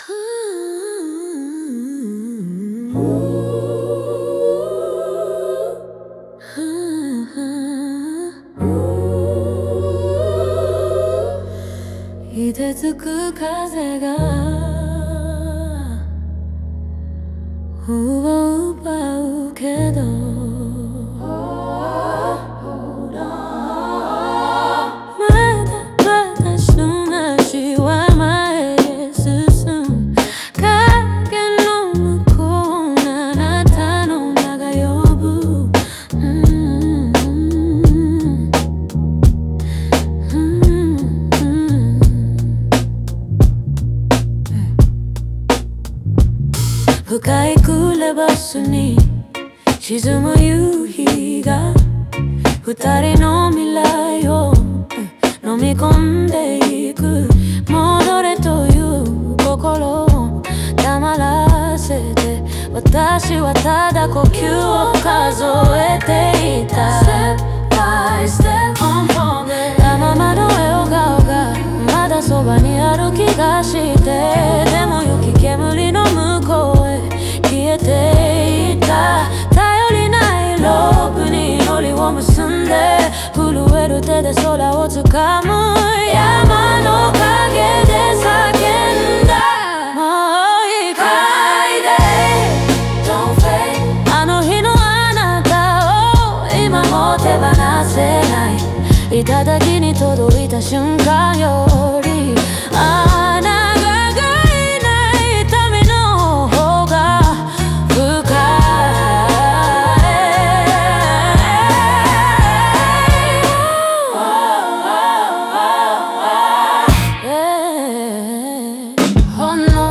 オリジナル曲♪
この歌詞は、極限の状況で失われた存在への“後悔と再生”を中心に構成し、ネオソウルとR&Bの情感表現を軸に描かれている。